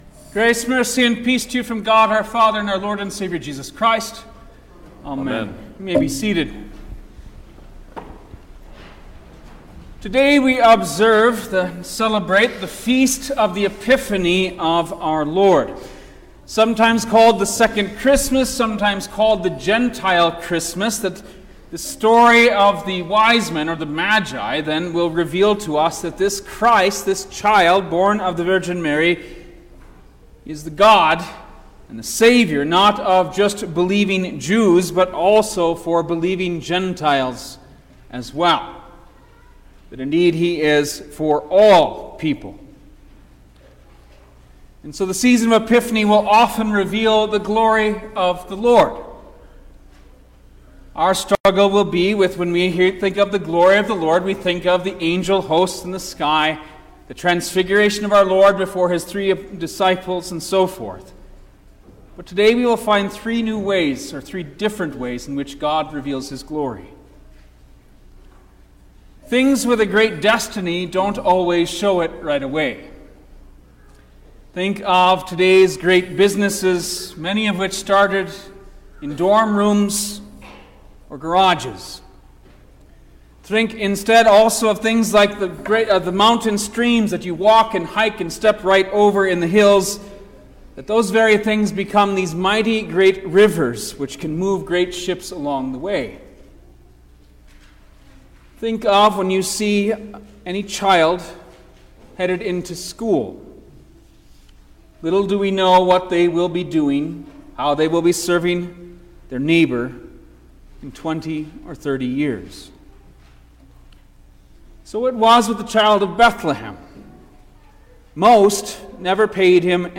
January-7_2024_The-Epiphany-of-Our-Lord_Sermon-Stereo.mp3